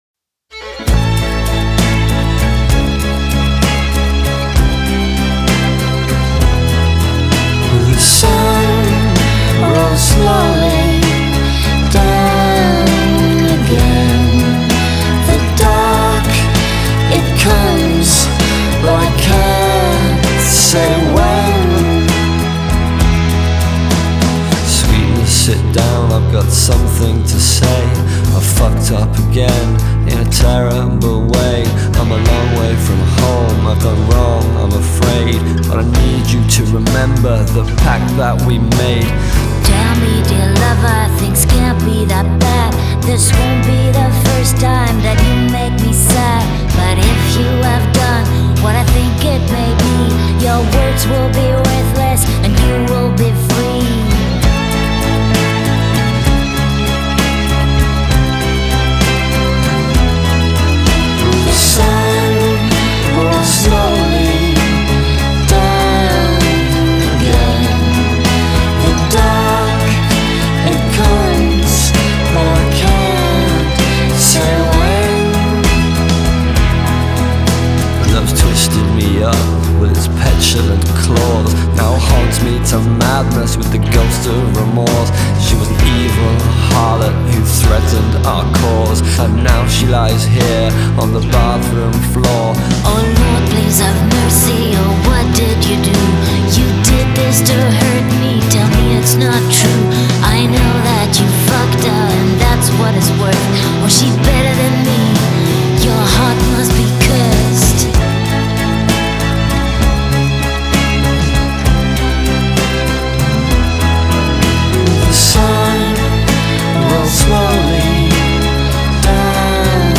Жанр: Pop, Chanson